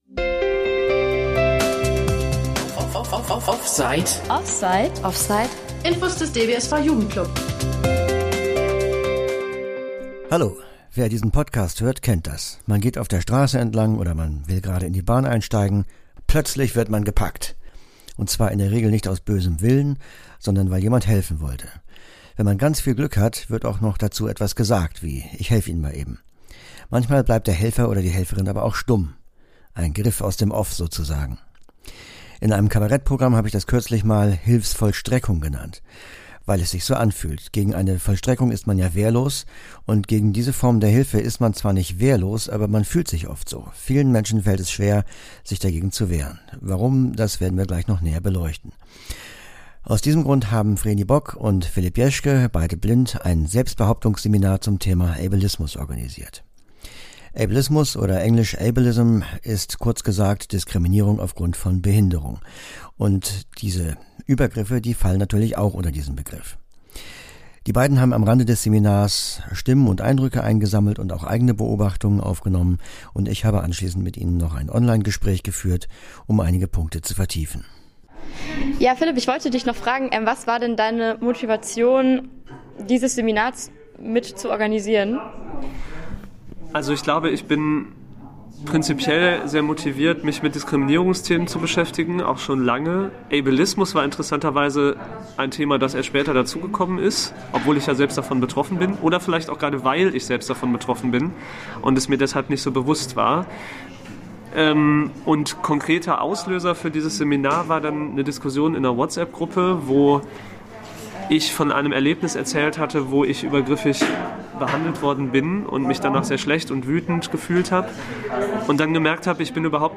Organisatorinnen des Seminars berichten über ihre Beweggründe, während Teilnehmende offen von ihren Erfahrungen mit Diskriminierung erzählen.